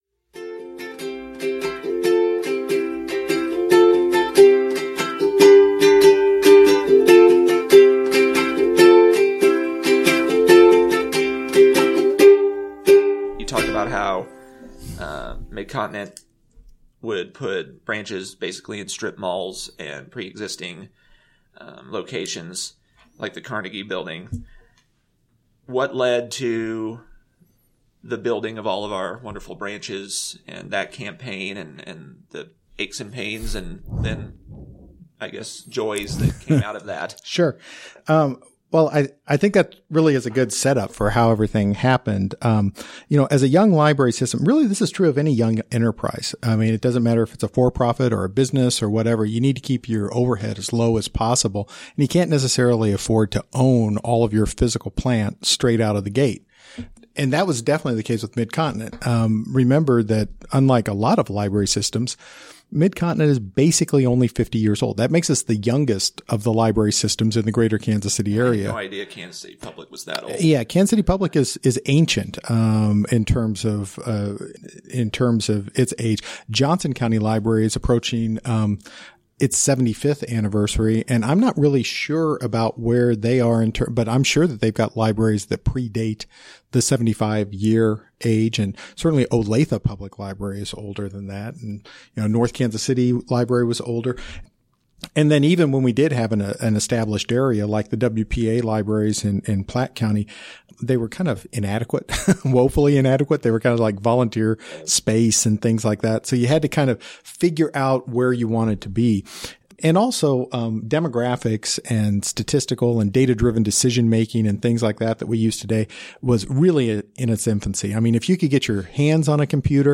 Part 2 Description Mid-Continent Public Library began in 1965. For its 50th anniversary, a series of interviews were recorded detailing the story of Mid-Continent Public Library.